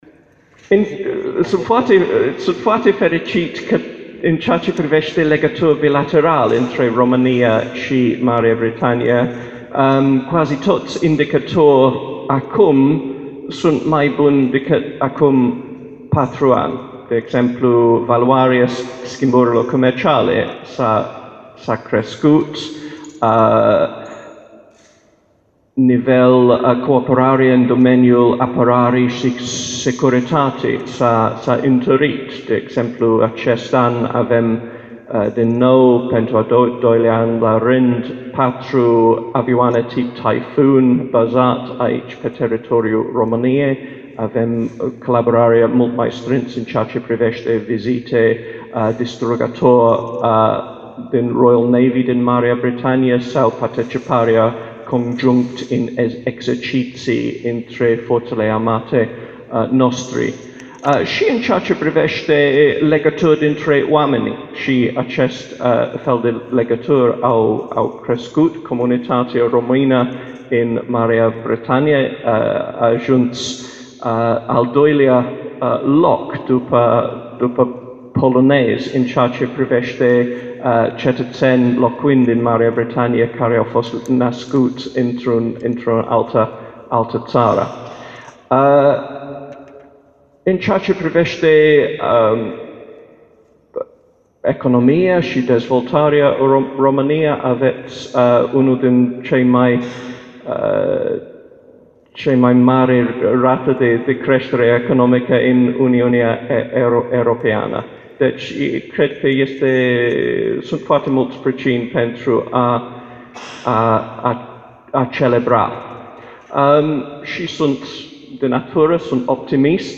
Conferința ambasadorului Marii Britanii, Paul Brummell( foto) pe teme de istorie, diplomație, dar și sociale, s-a desfășurat aștăzi în Sala a Voievozilor a Palatului Culturii.